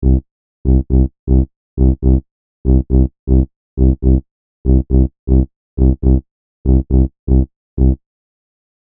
From there I put a tape delay on the right channel and set it to a 30 ms delay with no feedback and 100% wet.
After the delay, the sound is much fatter and natural.